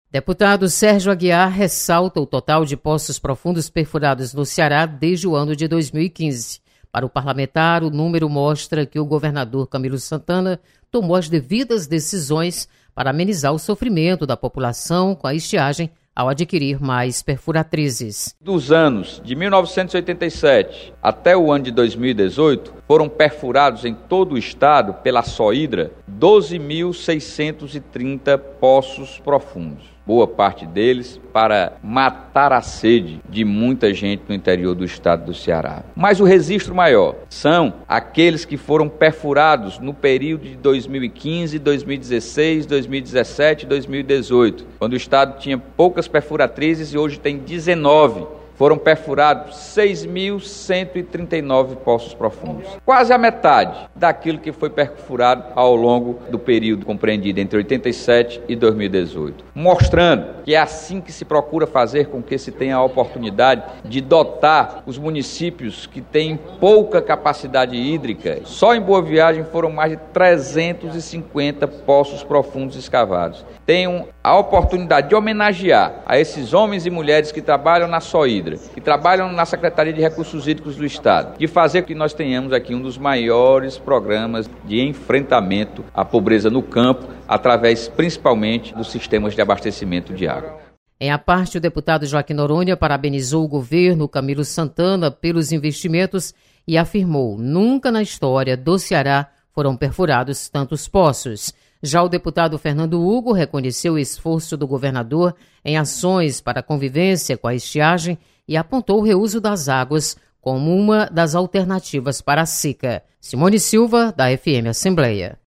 Deputados comentam sobre número de poços profundos perfurados para o enfrentamento da seca.